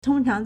通常 tōngcháng
tong1chang2.mp3